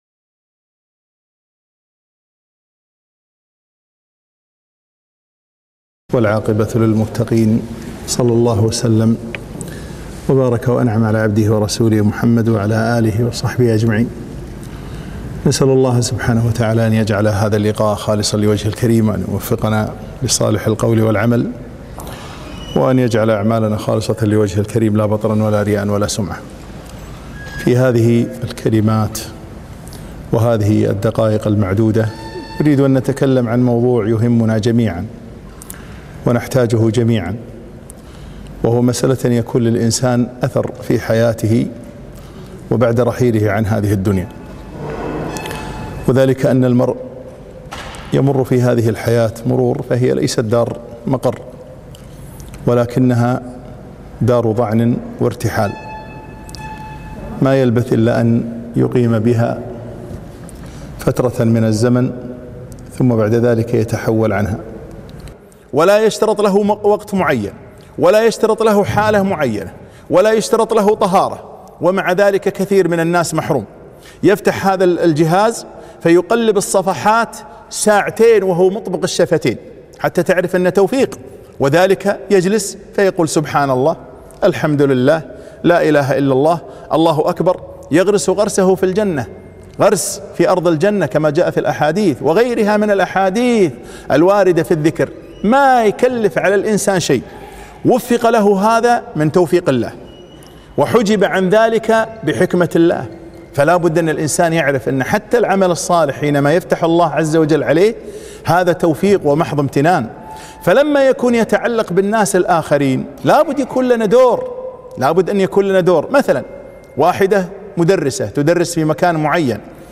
محاضرة - اتـرك أثـرا